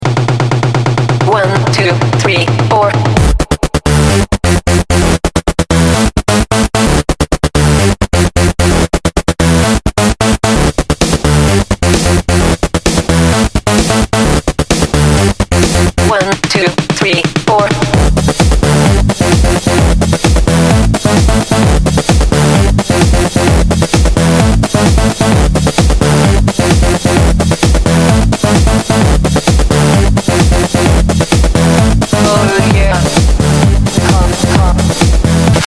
DNB
Ремикс от известного исполнителя